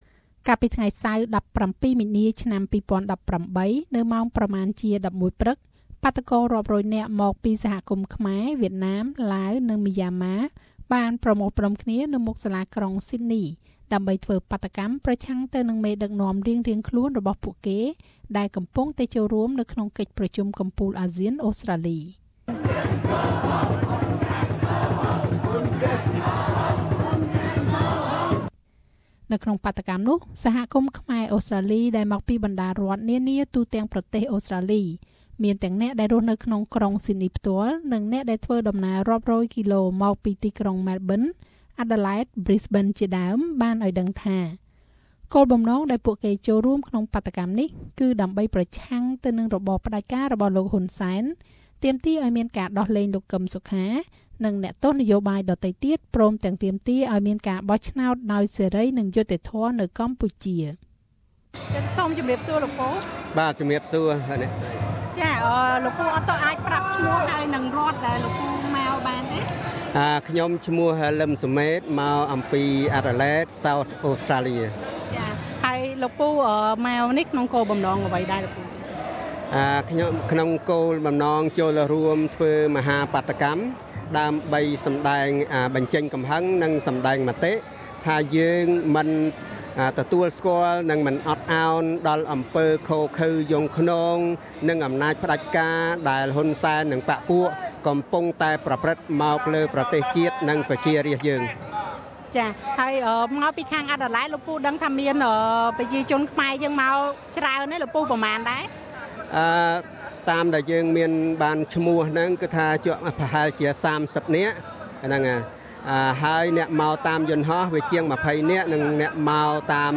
មតិយោបល់អ្នកដែលចូលរួមបាតុកម្មប្រឆាំងនឹងលោកនាយករដ្ឋមន្រ្តីហ៊ុន សែន នៅស៊ីដនី
កាលពីថ្ងៃសៅរ៍ទី១៧មីនាឆ្នាំ២០១៨ នៅម៉ោងប្រមាណជា១១ព្រឹក បាតុកររាប់រយនាក់មកពីសហគមន៍ខ្មែរ វៀតណាម ឡាវ និង មីយ៉ាម៉ា បានប្រមូលផ្តុំគ្នានៅមុខសាលាក្រុងស៊ីដនីដើម្បីធ្វើបាតុកម្មប្រឆាំងទៅនឹងមេដឹកនាំរៀងៗខ្លួនរបស់ពួកគេ ដែលកំពុងតែចូលរួមនៅក្នុងកិច្ចប្រជុំកំពូលអាស៊ាន អូស្រ្តាលី។ ហើយនេះគឺជាមតិយោបល់របស់អ្នកដែលចូលរួមនៅក្នុងបាតុកម្មនាថ្ងៃនោះ។